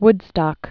(wdstŏk)